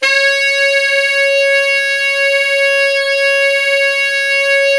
SAX_sfc#5bx  244.wav